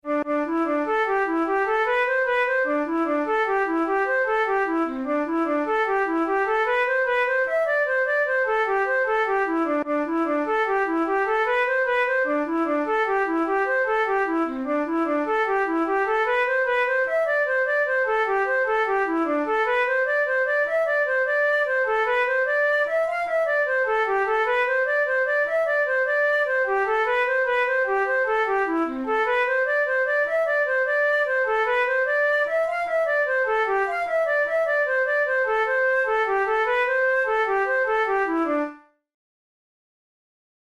InstrumentationFlute solo
KeyD minor
Time signature6/8
Tempo100 BPM
Jigs, Traditional/Folk
Traditional Irish jig